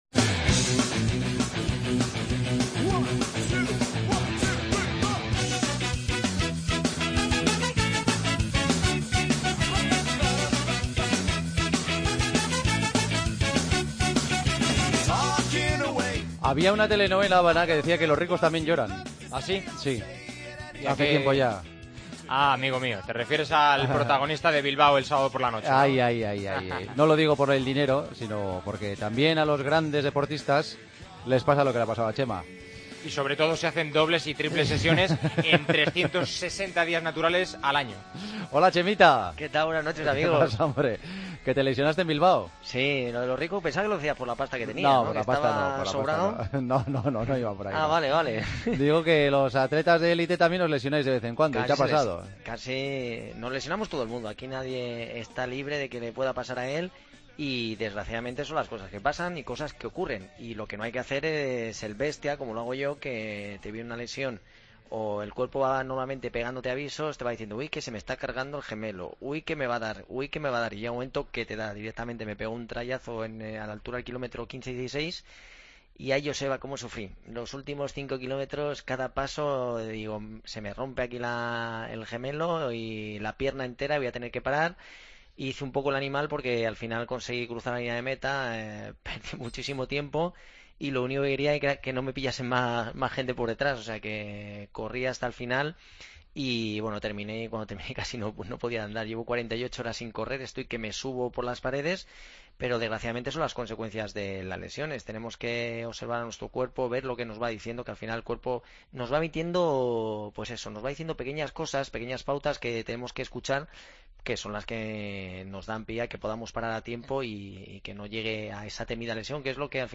AUDIO: Con Chema Martínez, lesionado esta semana, y su consultorio con los oyentes, incluído su propio análisis de los males de Gareth Bale.